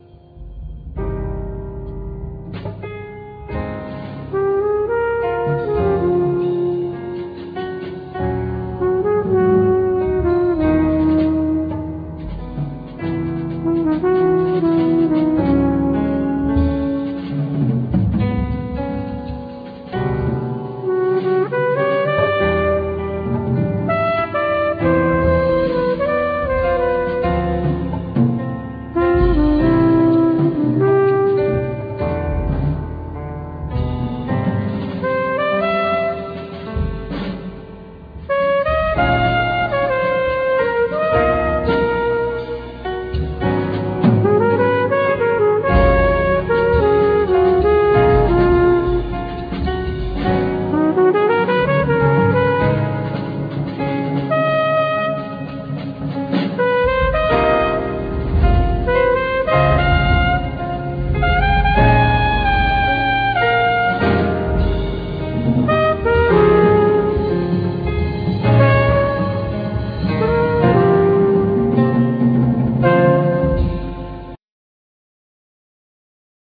Trumpet
Piano
Percussion